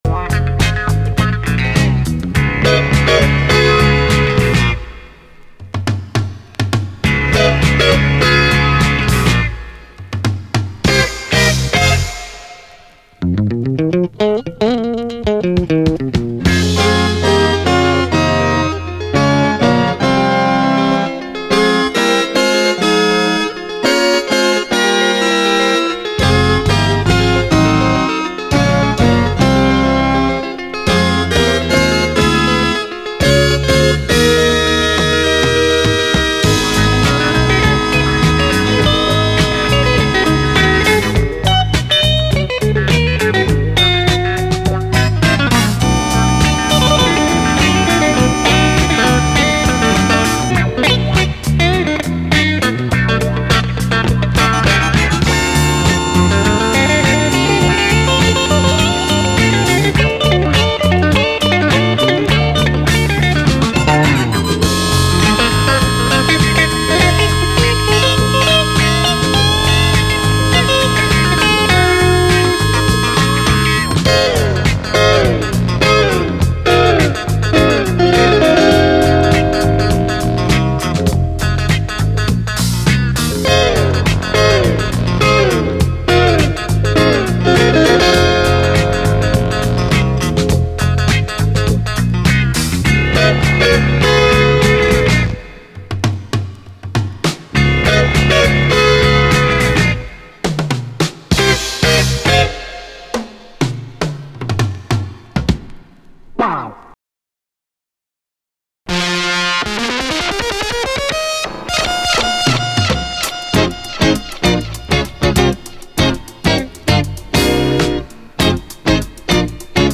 JAZZ FUNK / SOUL JAZZ, SOUL, 70's ROCK, 70's～ SOUL, ROCK